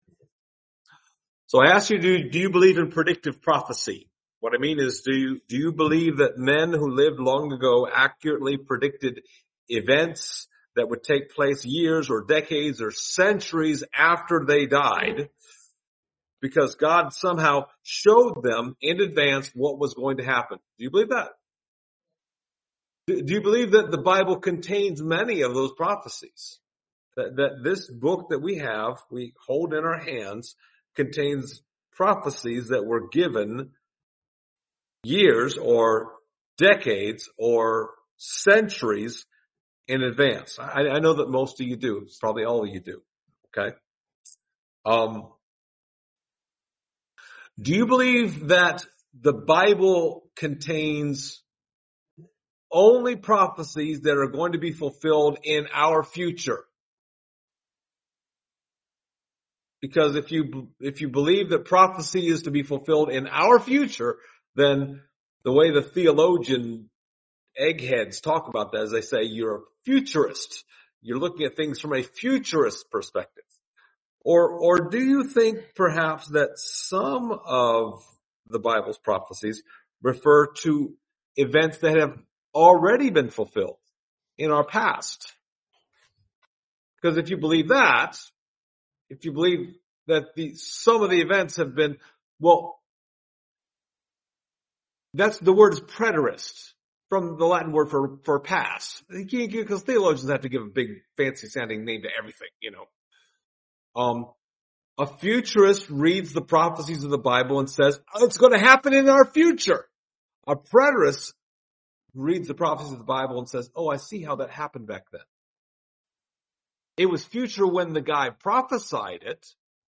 Passage: Micah 5:2-5 Service Type: Sunday Morning